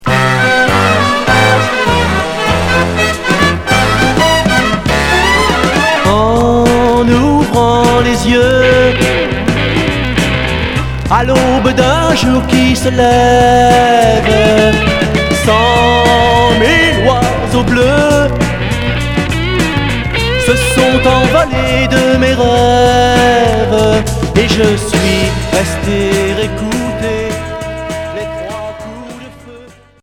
Chanteur 60's Unique 45t retour à l'accueil